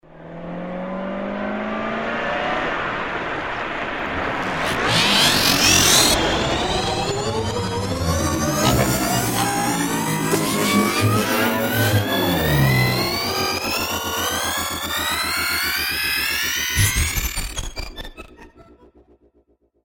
transformer-fx_24944.mp3